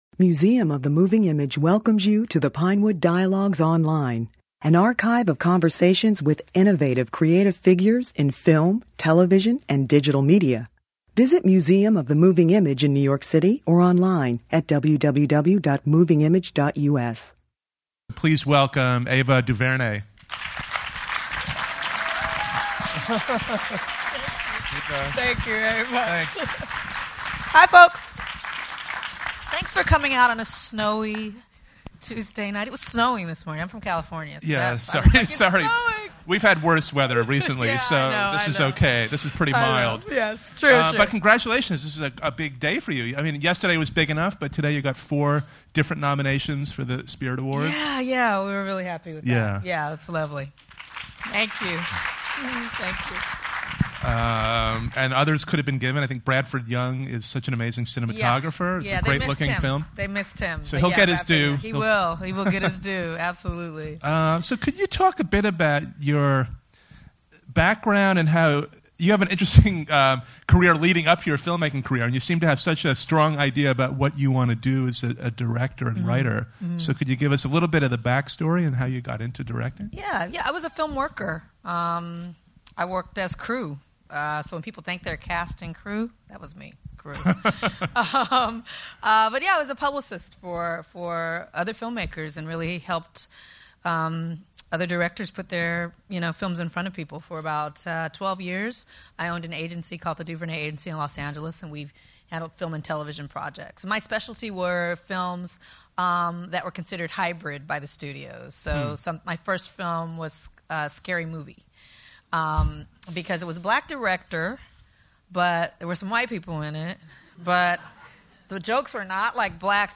After a special screening at Museum of the Moving Image, DuVernay spoke about the challenges behind making and distributing the film.